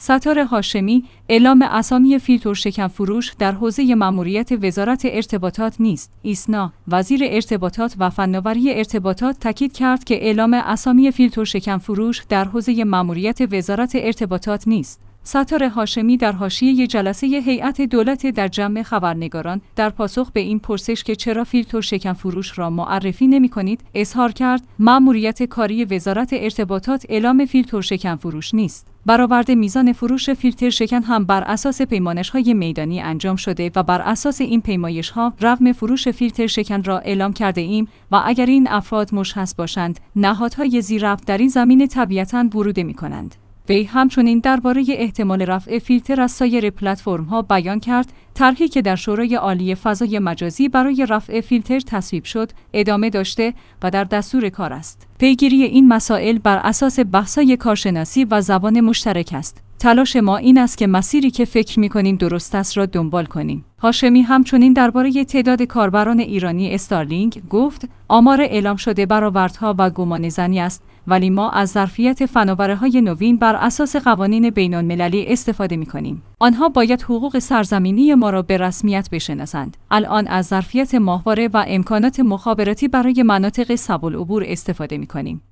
ستار هاشمی در حاشیه جلسه هیئت دولت در جمع خبرنگاران در پاسخ به این پرسش که چرا فیلترشکن فروش‌ها را معرفی نمی‌کنید، اظهار کرد: ماموریت کاری وزارت ارتباطات اعلام فیلترشکن فروش نیست.